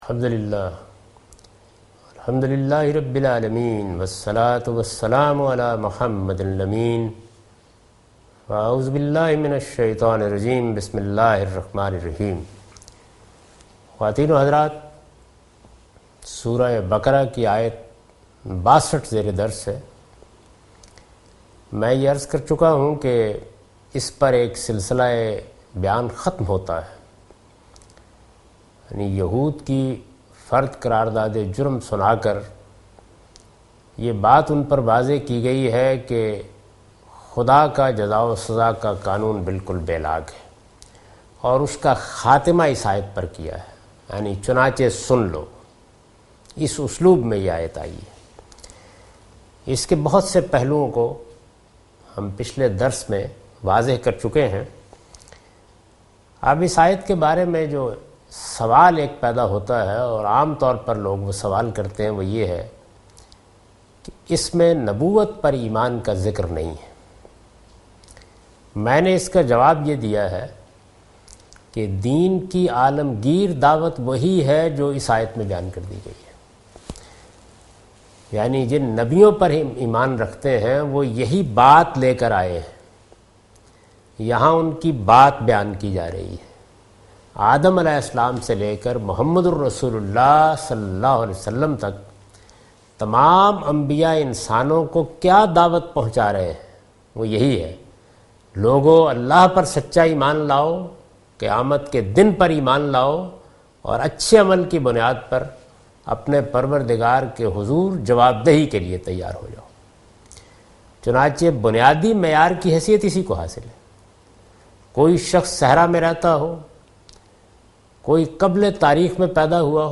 Surah Al-Baqarah - A lecture of Tafseer-ul-Quran – Al-Bayan by Javed Ahmad Ghamidi. Commentary and explanation of verse 62,63,64,65,66 and 67 (Lecture recorded on 30th May 2013).